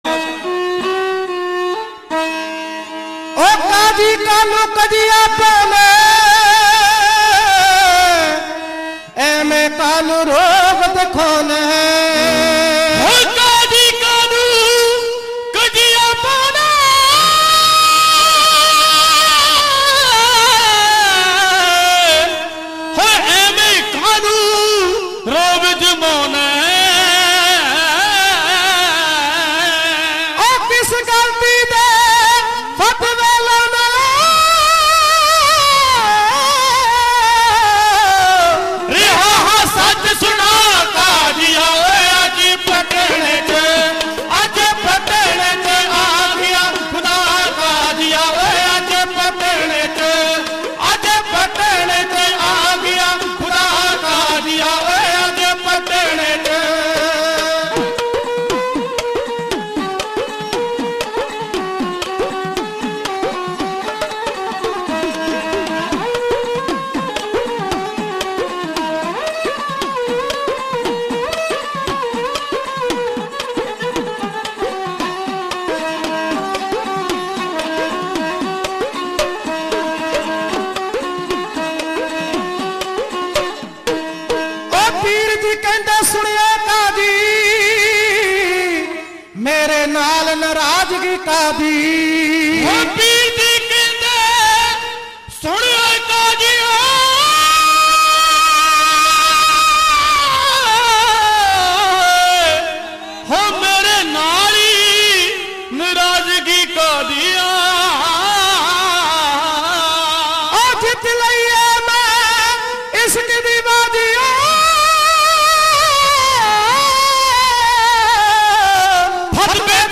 Genre: Dhadi Varan